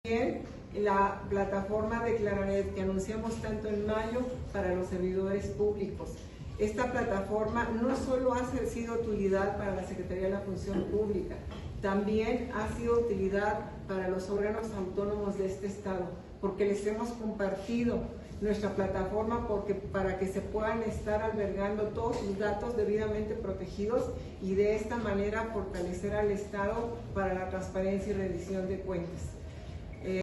AUDIO: MARÍA DE LOS ÁNGELES ALVARÉZ, SECRETARÍA DE LA FUNCIÓN PÚBLICA (SFP)